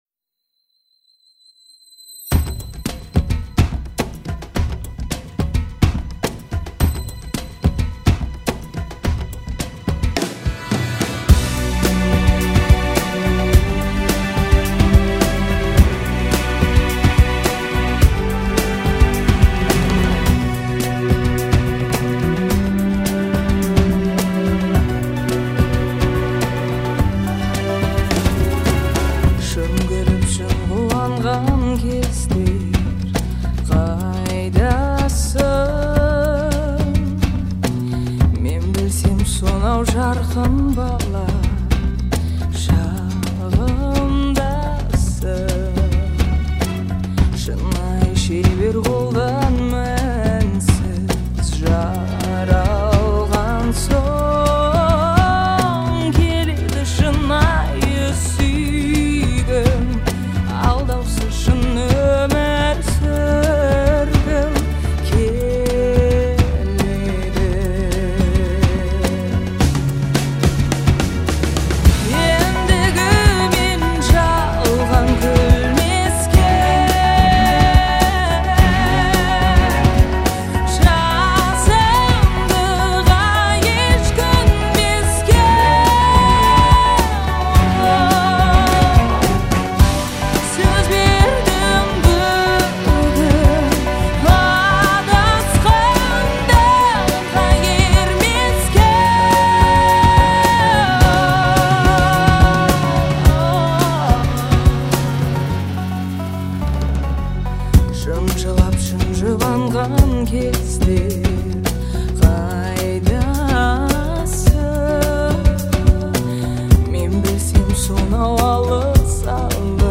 это трек в жанре поп с элементами восточной музыки
Звучание песни отличается мелодичностью и гармонией
Благодаря ритмичному биту и ярким инструментальным элементам